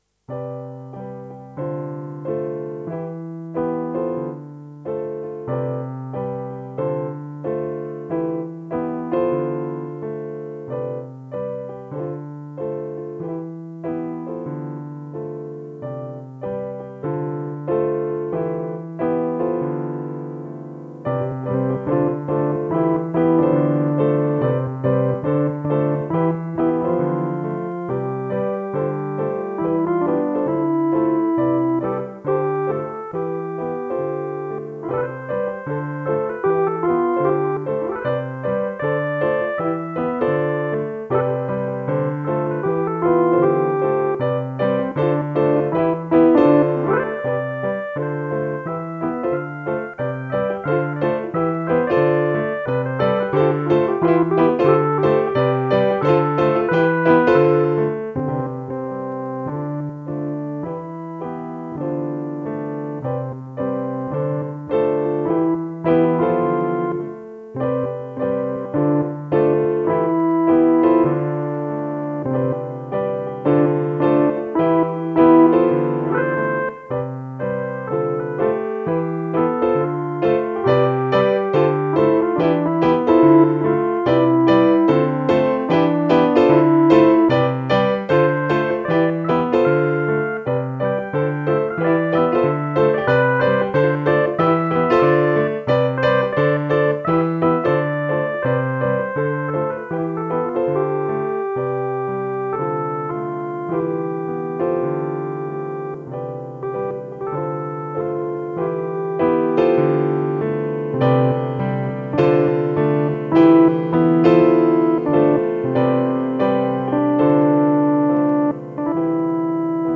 Relaxte Romantik, oldstyle, Piano mit Orgel im Duett.